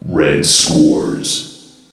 voc_red_scores.ogg